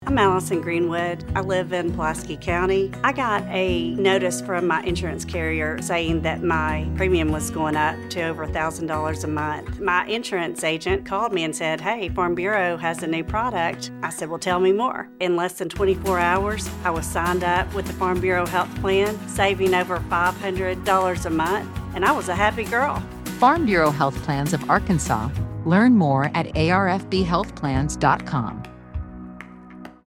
• Allow new members to tell their stories in their own words versus following a script.